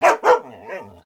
bdog_idle_3.ogg